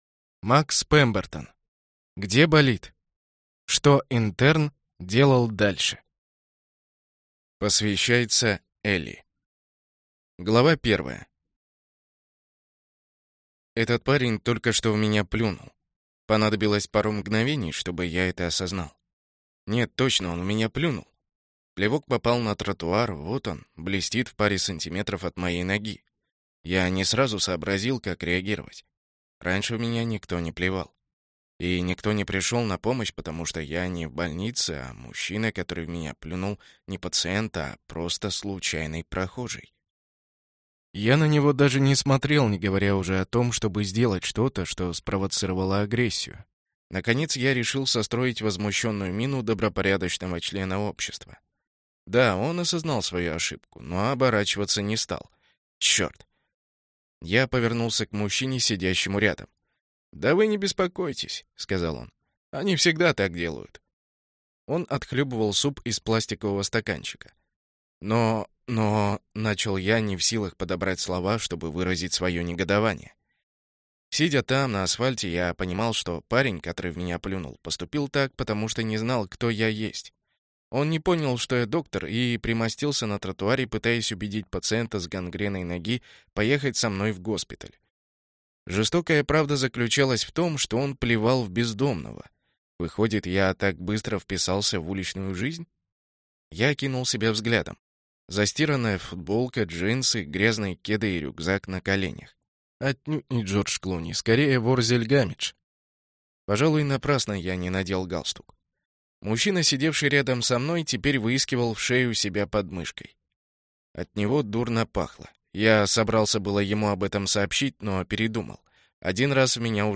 Аудиокнига Где болит? Что интерн делал дальше | Библиотека аудиокниг